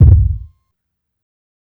ILLMD001_KICK_DIE.wav